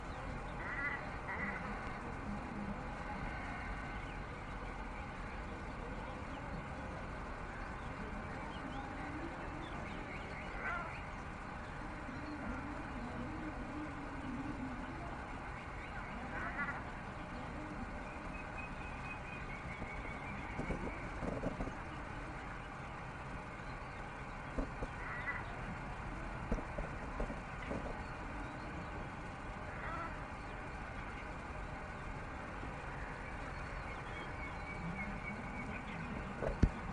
彩鹮叫声是带鼻音的咕哝声或咩咩咕咕的叫声